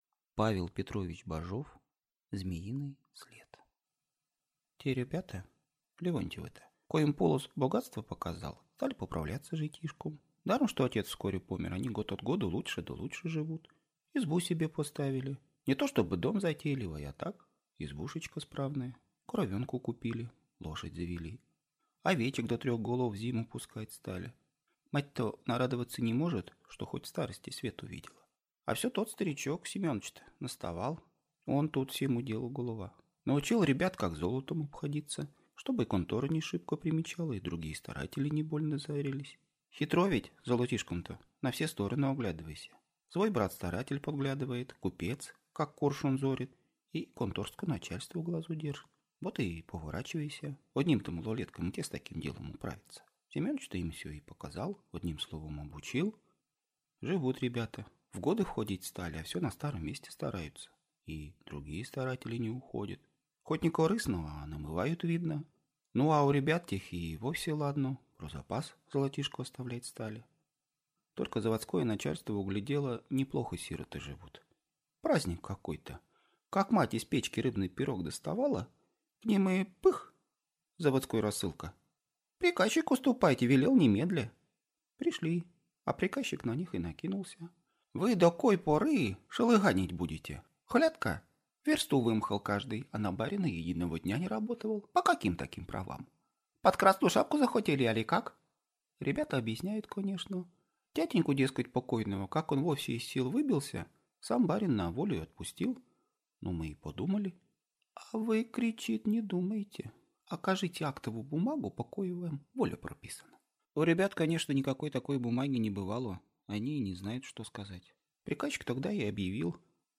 Аудиокнига Змеиный след | Библиотека аудиокниг